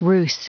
Prononciation du mot ruse en anglais (fichier audio)
Vous êtes ici : Cours d'anglais > Outils | Audio/Vidéo > Lire un mot à haute voix > Lire le mot ruse